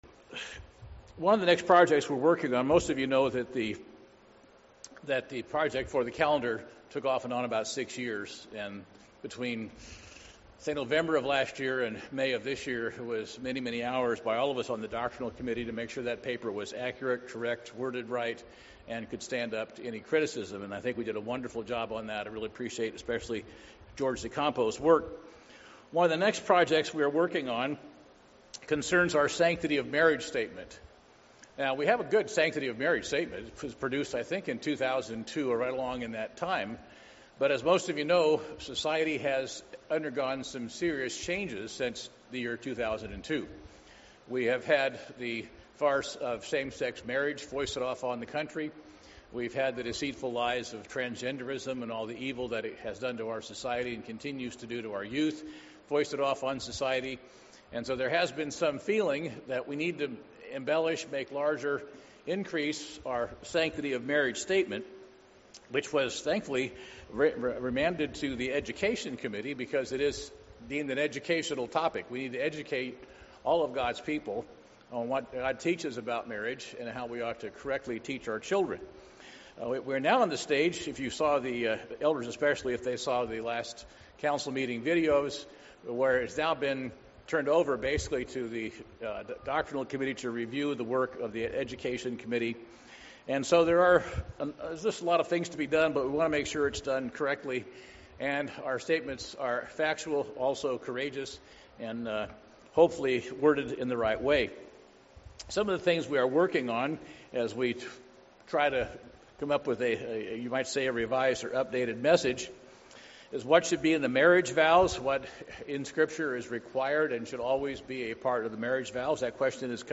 In response to societal attitudes, the United Church of God may soon update their statement on marriage. Considering the prudence of something more comprehensive, this sermon boldly examines the Biblical relevance of various passages.